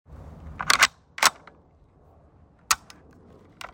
M1-carbine.mp3